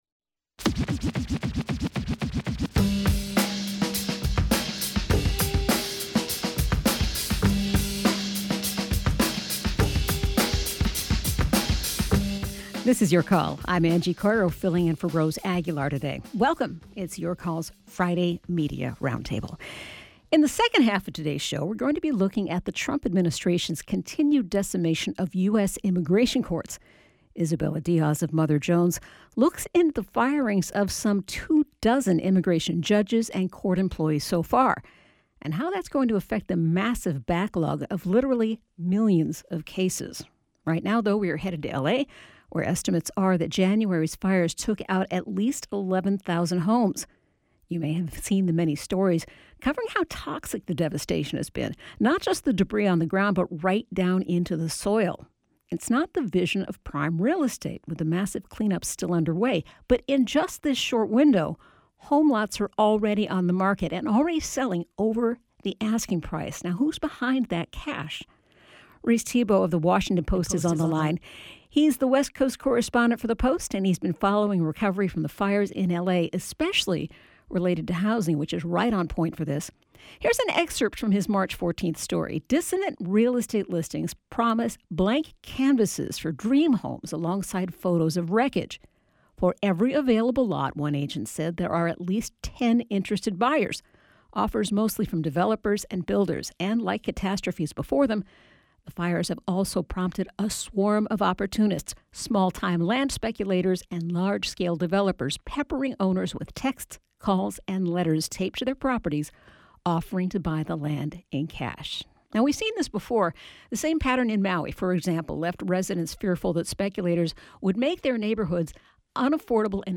KALW's call-in show: Politics and culture, dialogue and debate.